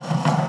wood_m1.wav